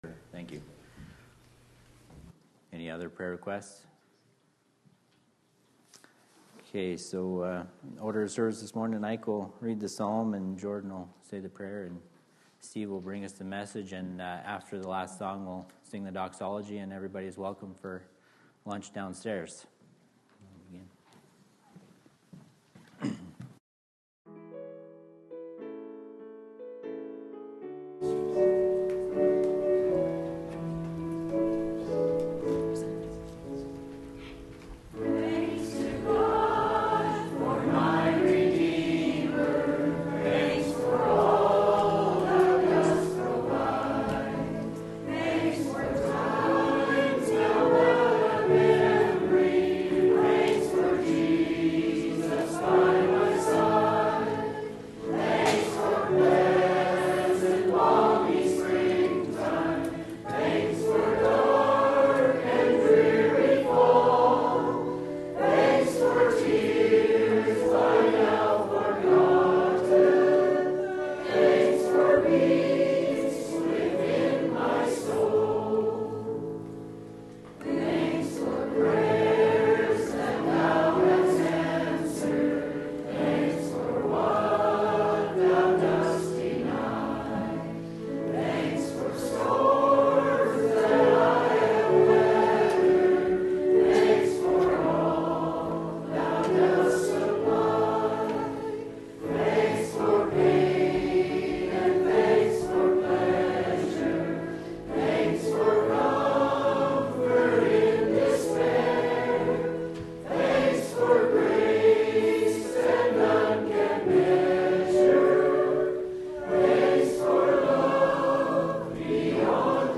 Sylvan Lake Apostolic Lutheran Church